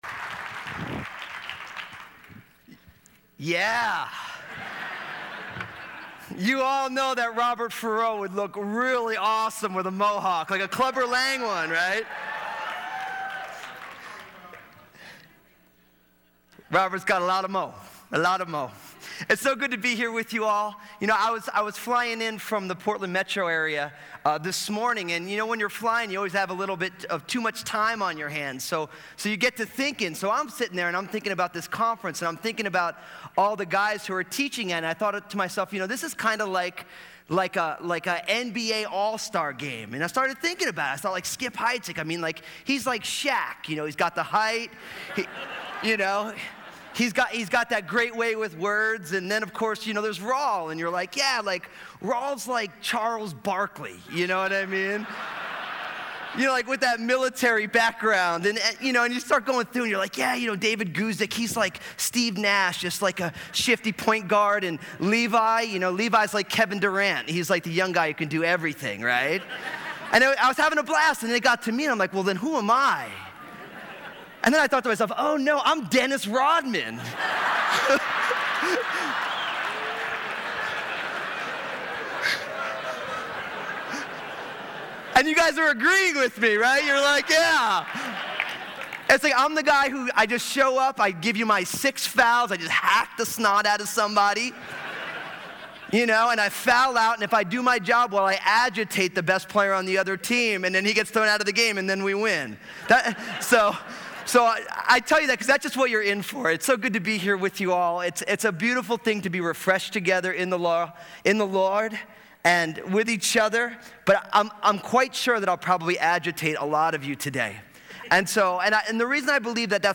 at the 2013 SW Pastors and Leaders Conference, "Courage"